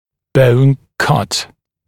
[bəun kʌt][боун кат]разрез кости